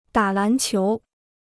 打篮球 (dá lán qiú) Free Chinese Dictionary